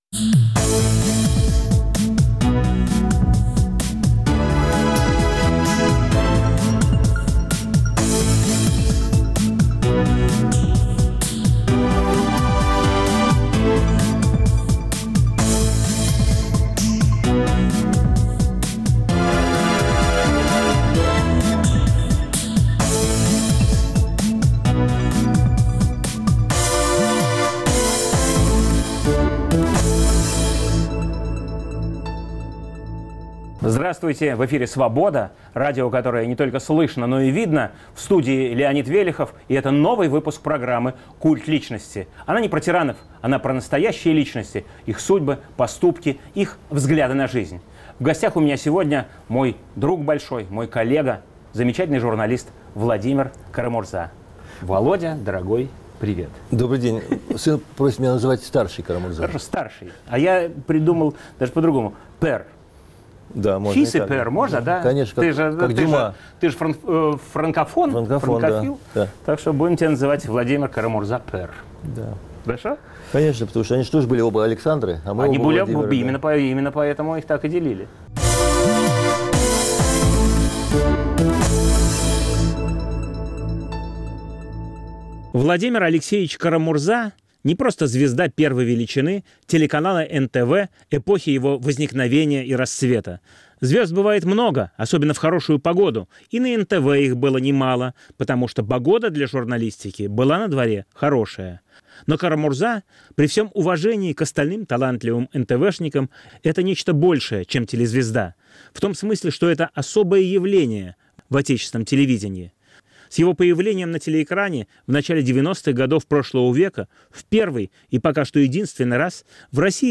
Программа о настоящих личностях, их судьбах, поступках и взглядах на жизнь. В студии знаменитый теле- и радиоведущий Владимир Кара-Мурза.